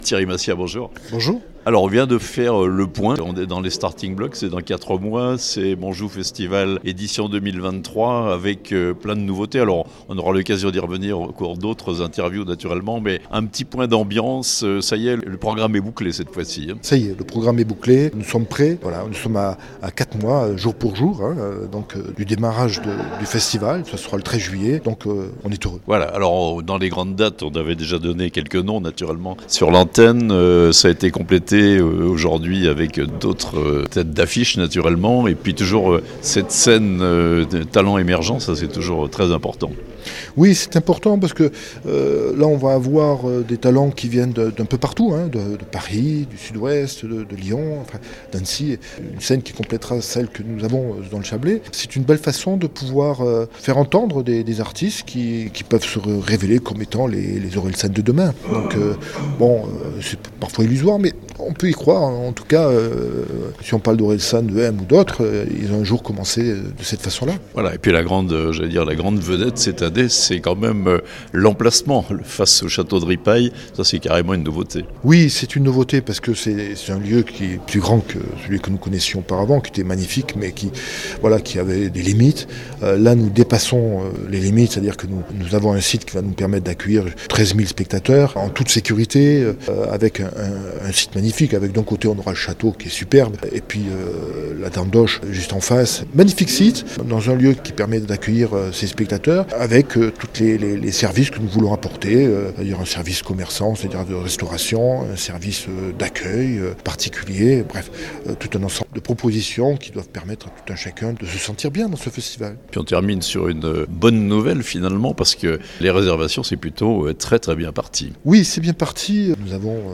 au micro La Radio Plus